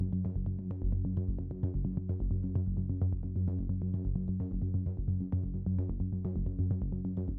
合成器低音滚动
描述：一个大的厚实的合成低音，与其他循环的效果很好。
Tag: 95 bpm Electronic Loops Bass Loops 1.70 MB wav Key : G